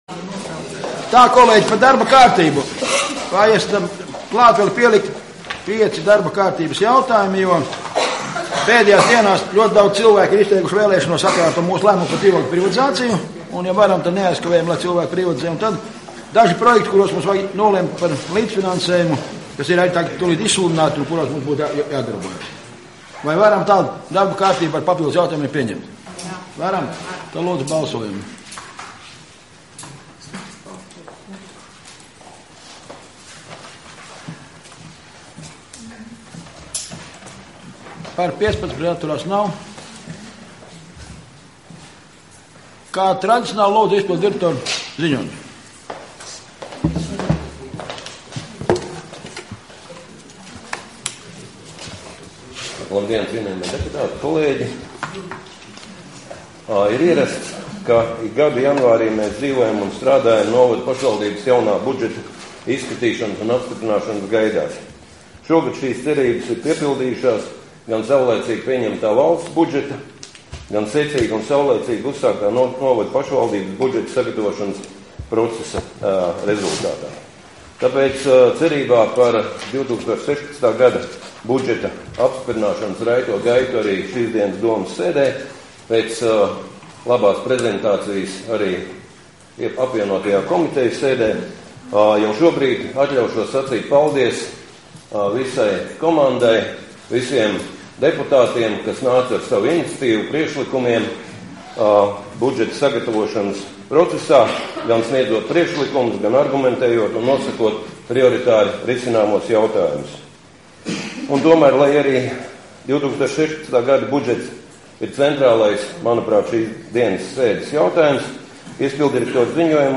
Domes sēde Nr. 1